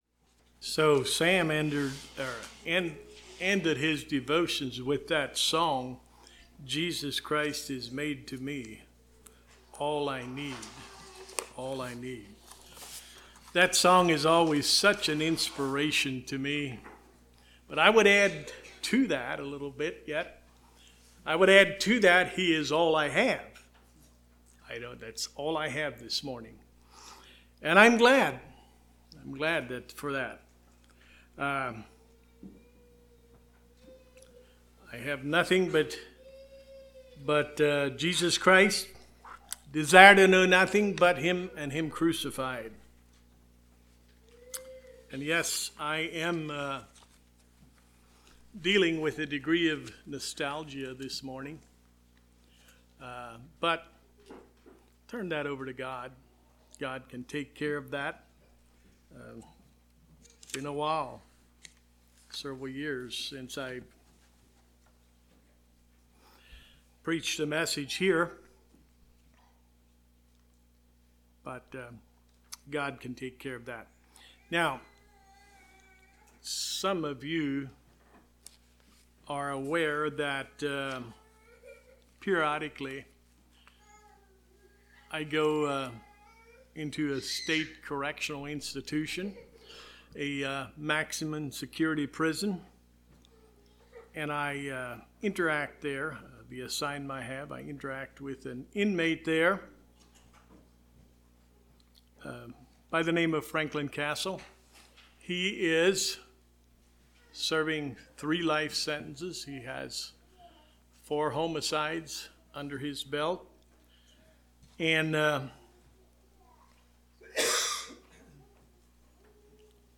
Service Type: Sunday Morning Topics: Spiritual Death , Spiritual Life « Intercessory Prayer